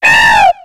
Cri d'Arkéapti dans Pokémon X et Y.